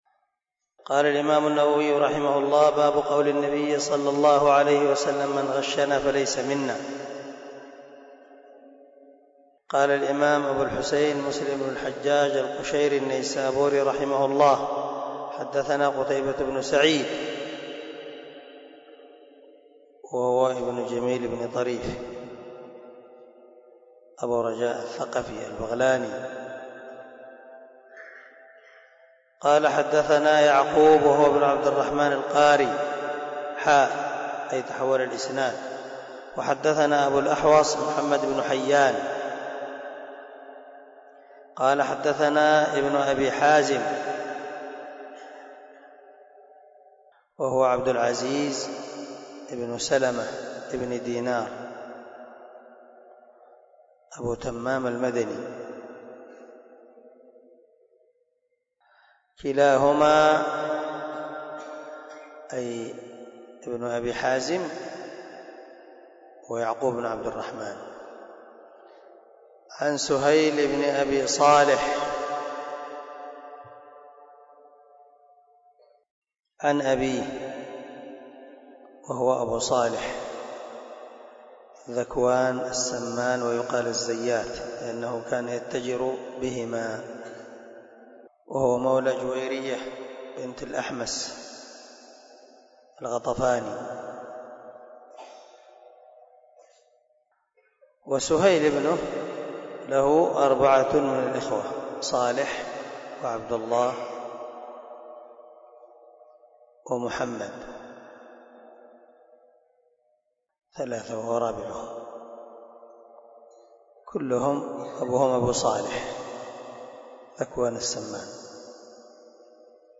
070الدرس 69 من شرح كتاب الإيمان حديث رقم ( 101 - 102 ) من صحيح مسلم
دار الحديث- المَحاوِلة- الصبيحة.